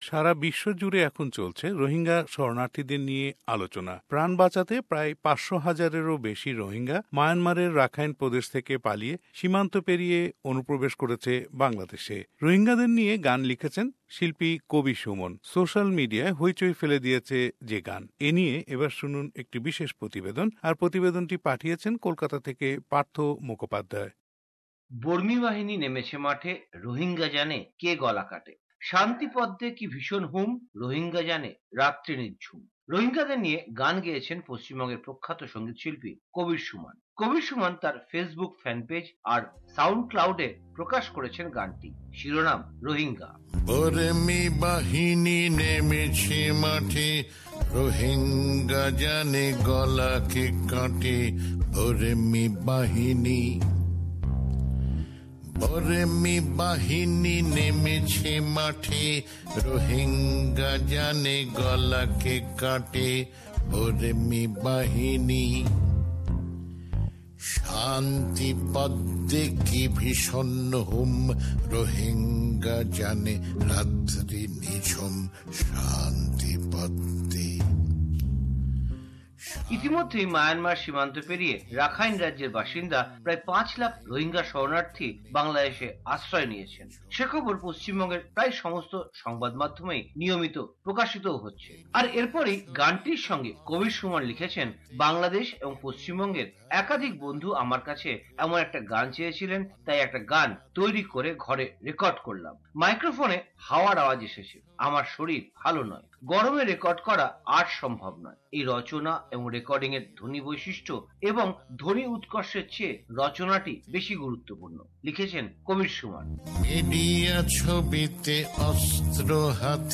The song takes an emotional, deeply humanistic tone